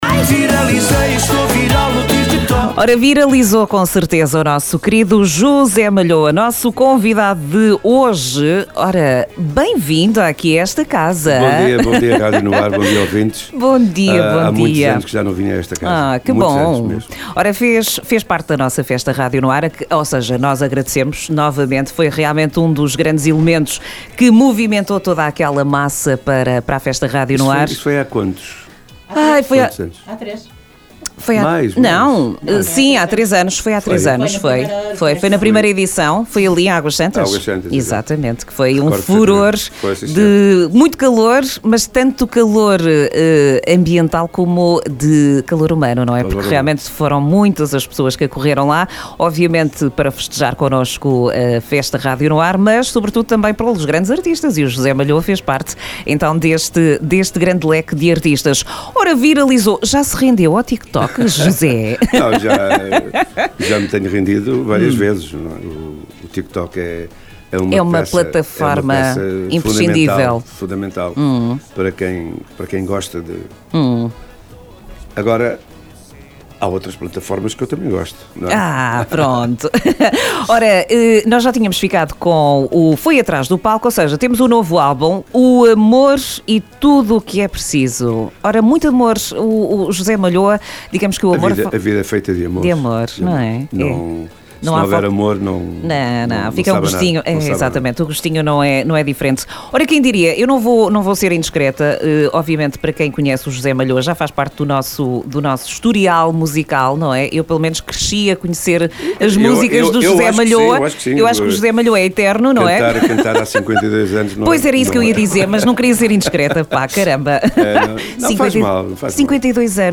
Entrevista a José Malhoa, dia 28 de Maio, em direto no programa da Manhã